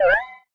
low_health_beep_03.ogg